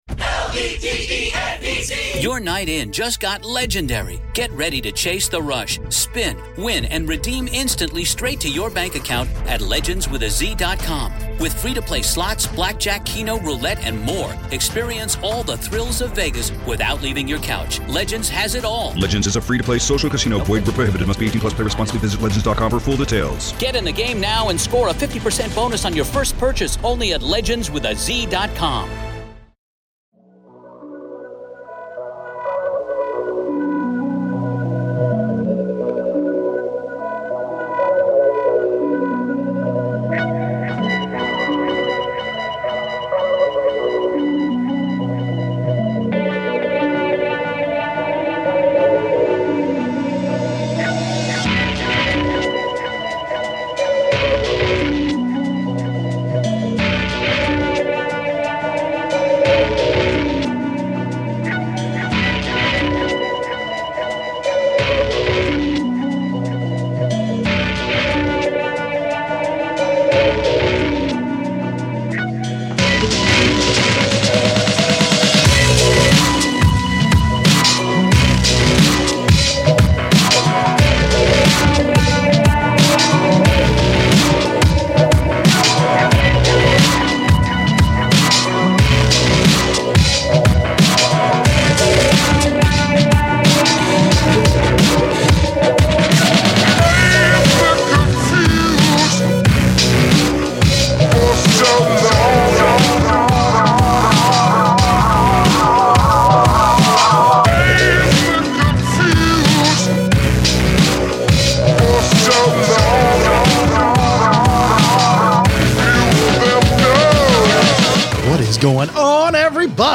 After the break the guys interview